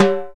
RX TIMBALE.wav